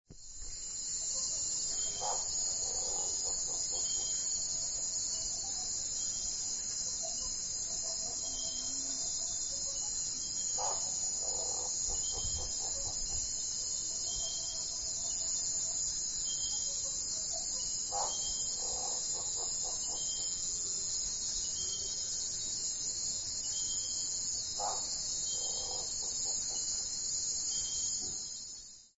Night in the Amazon rainforest.
This may or may not be a ten pound frog.
bignoisyfrogthing.mp3